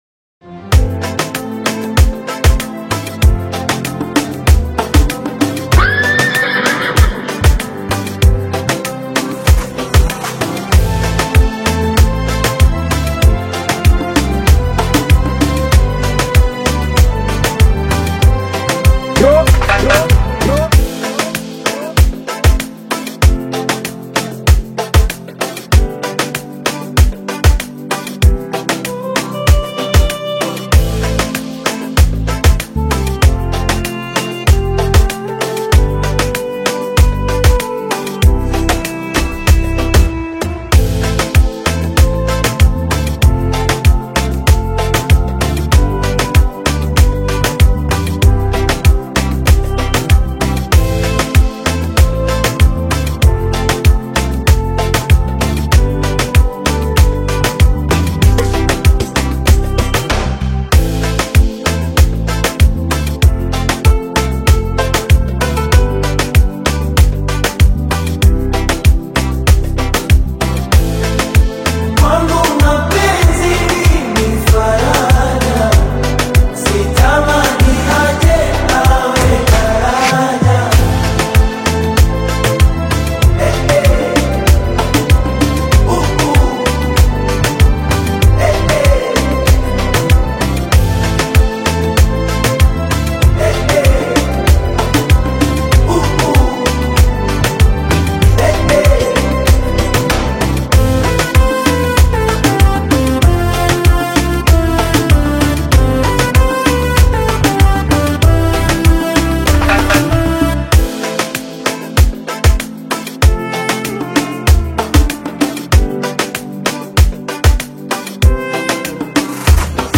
AudioBongo flava
Afro-Beat single